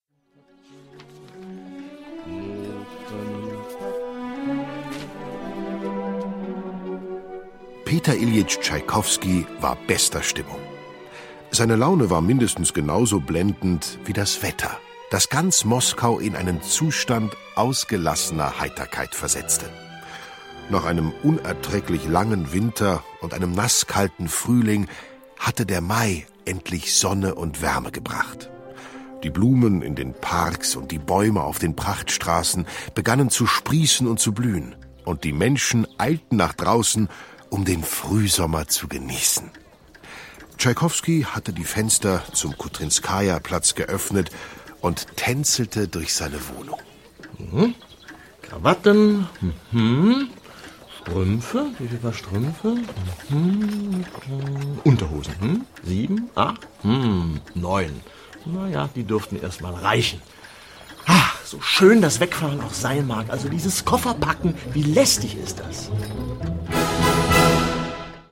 Sunnyi Melles, Udo Wachtveitl (Sprecher)
Diese Hörspielbox erzählt von der Entstehung der märchenhaften Werke und lässt anschließend ihre schönsten Passagen erklingen.
Schlagworte Ballett • Der Nussknacker • Hörbuch; Lesung für Kinder/Jugendliche • Klassik für Kinder • Klassische Musik • Schwanensee • Tschaikowski, Peter I.; Kindersachbuch/Jugendsachbuch • Tschaikowsky